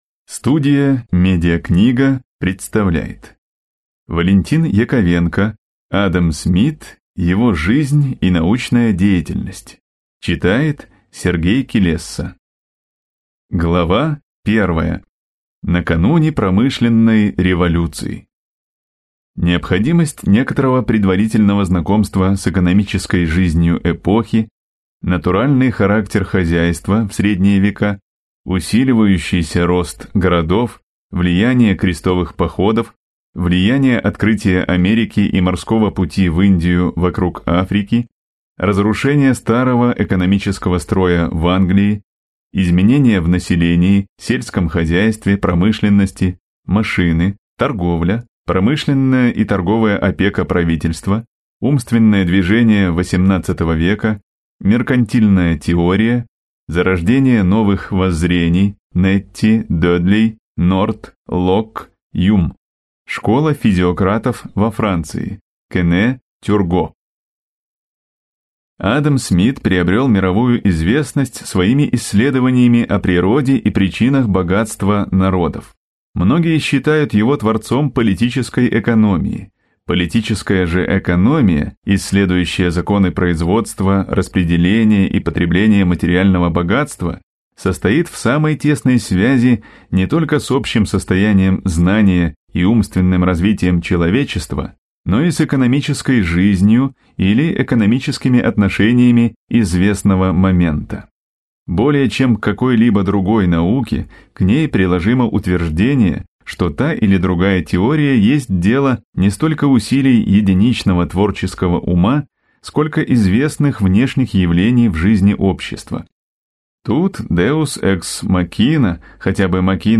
Аудиокнига Адам Смит. Его жизнь и научная деятельность | Библиотека аудиокниг